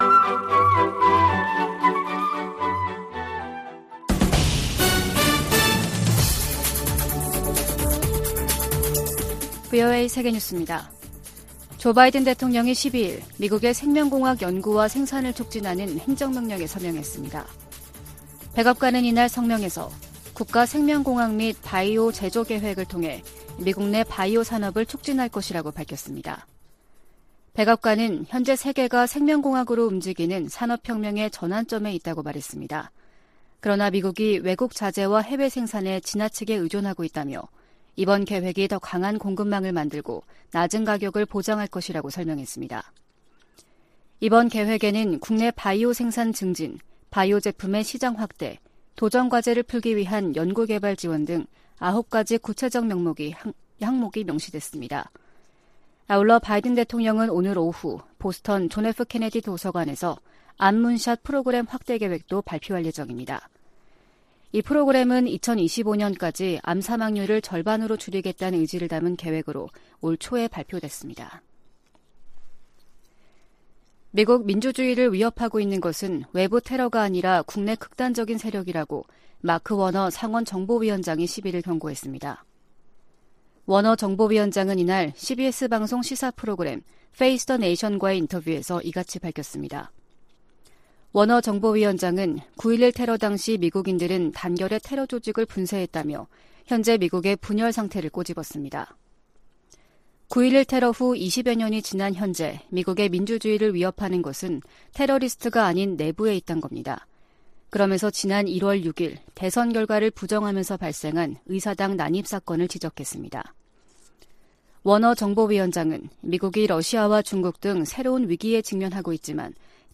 VOA 한국어 아침 뉴스 프로그램 '워싱턴 뉴스 광장' 2022년 9월 13일 방송입니다. 북한이 경제난 속 ‘핵 법제화’를 강행하는 것은 중러와의 3각 밀착이 뒷받침을 하고 있다는 분석이 나오고 있습니다.